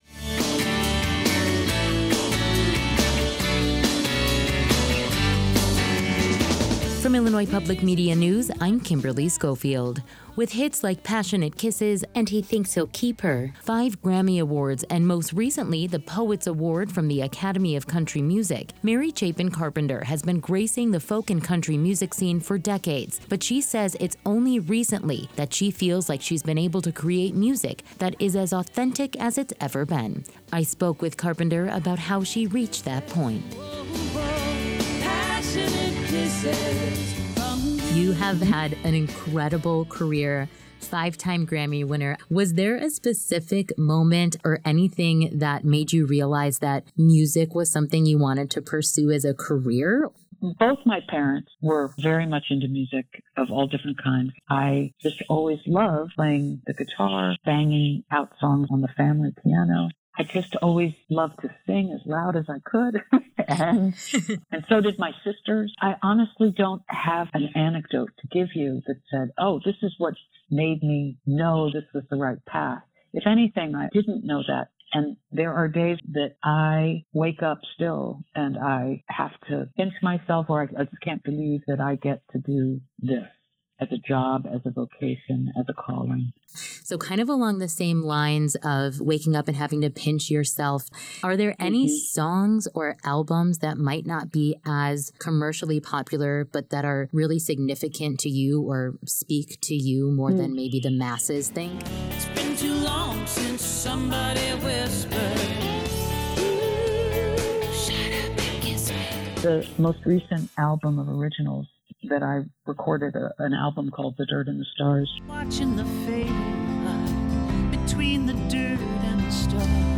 But she says it’s only recently that she feels like she’s been able to create music that is as authentic as it’s ever been. I spoke with Carpenter about how she reached that point.